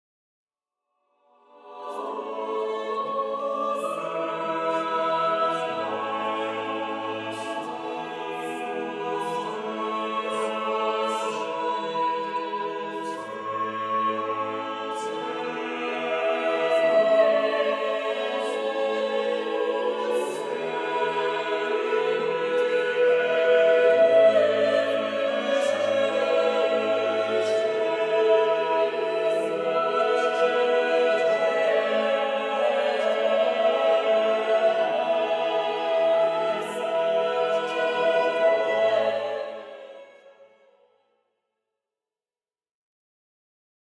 Messe à quatre voix - Messa a quattro voci
Format :MP3 256Kbps Stéréo